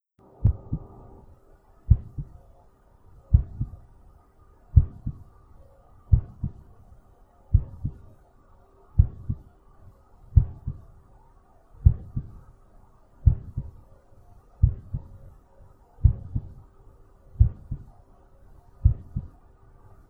인간의 심장
42bpm의 서맥을 보이는 엘리트 운동선수의 안정 시 심박수 기록